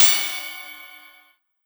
Crash And Cymbal